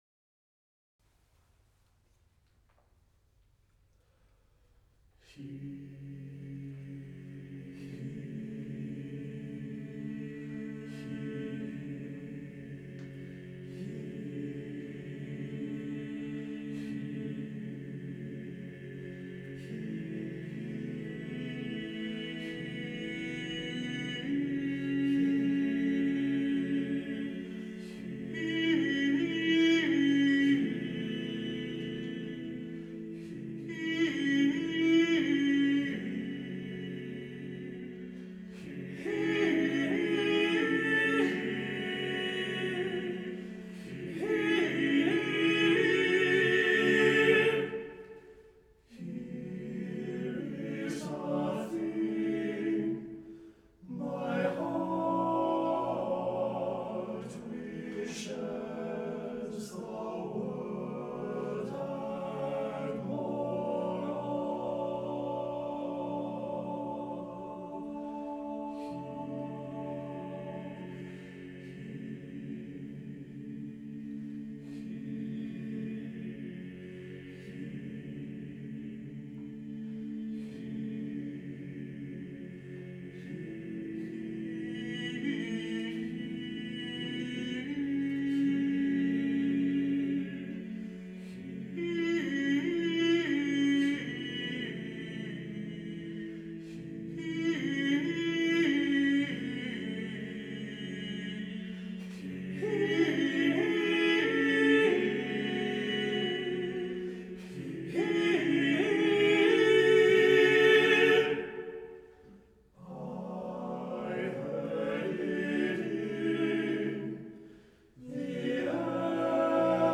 TTBB a cappella chorus with soli